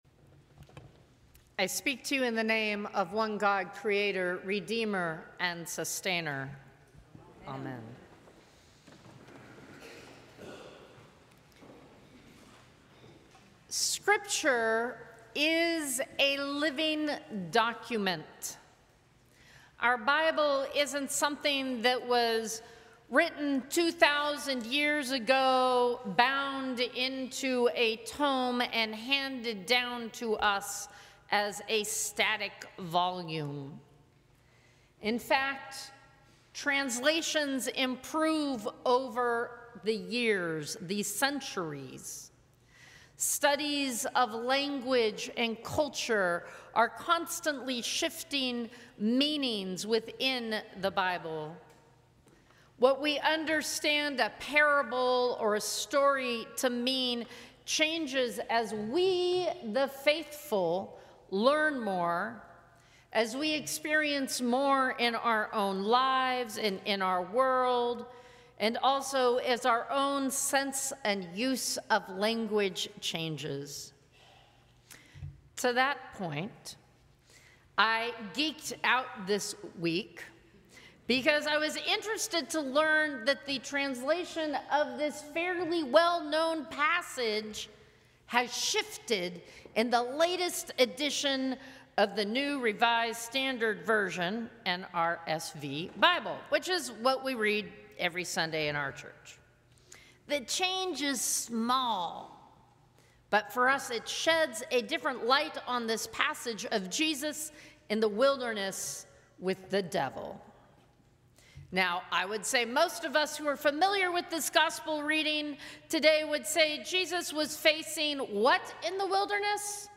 Sermons from St. Cross Episcopal Church First Sunday in Lent Mar 11 2025 | 00:11:13 1x 00:00 / 00:11:13 Subscribe Share Apple Podcasts Spotify Overcast RSS Feed Share Link Embed